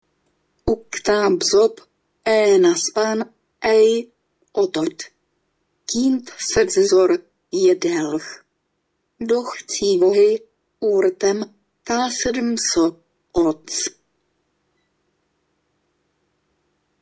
Je to krátký mluvený text, který začíná slovy UKTAAP ZOP uvedenými na zadání. Mluvené slovo je však nesrozumitelné, skoro jako by ho mluvil nějaký "maďar". Nebo spíše Maďarka, protože hlas je to dívčí.
Nahraný text je tedy čtený pozpátku.